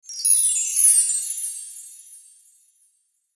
Звуки дзынь
На этой странице собраны разнообразные звуки «дзынь» — от легких металлических перезвонов до игривых хрустальных ноток.
Шепот волшебной магии